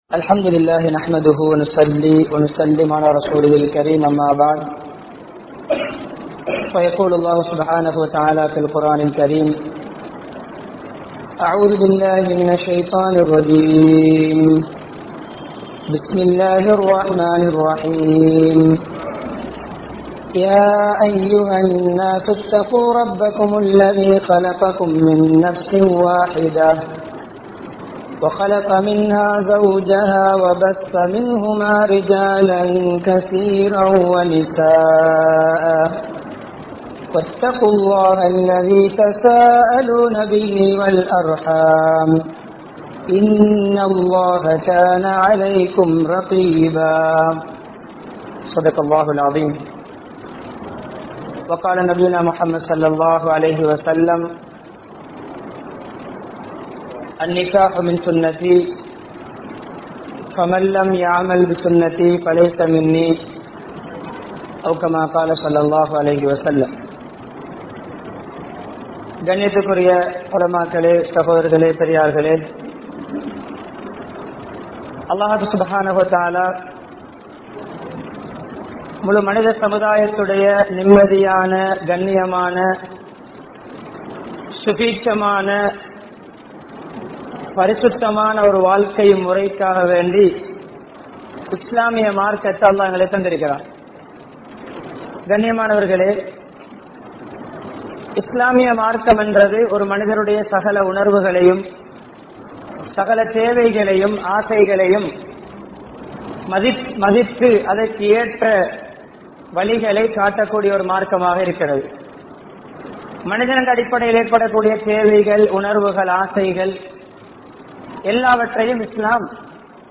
Nabi(SAW)Avarhalin Mun Maathiriyana Kudumba Vaalkai (நபி(ஸல்)அவர்களின் முன்மாதிரியான குடும்ப வாழ்க்கை) | Audio Bayans | All Ceylon Muslim Youth Community | Addalaichenai
Kaduwela, Weliwita Araliya Mawatta Jumua Masjidh